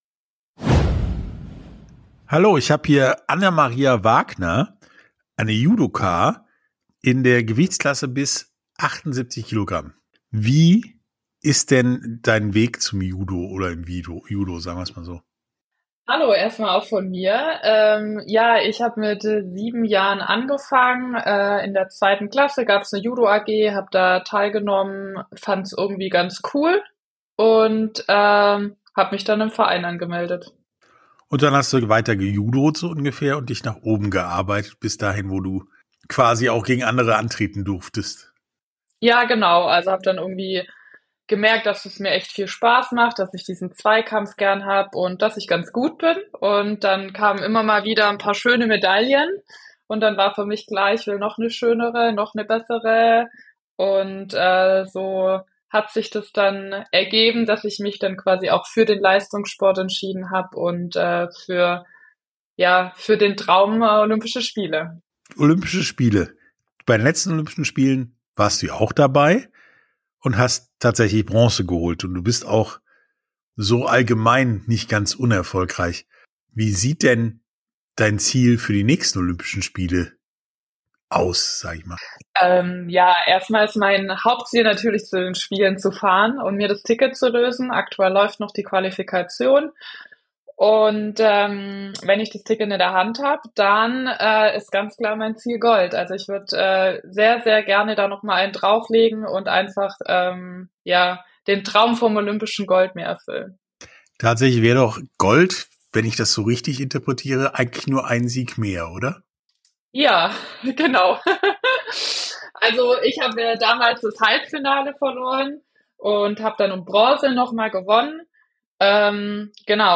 Sportstunde - Interview komplett Anna Maria Wagner ~ Sportstunde - Interviews in voller Länge Podcast